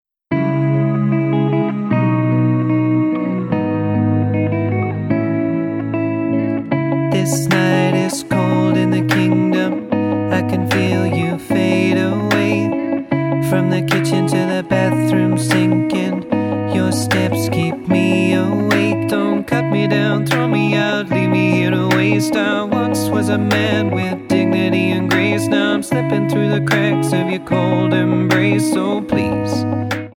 Tonart:C#m Multifile (kein Sofortdownload.
Die besten Playbacks Instrumentals und Karaoke Versionen .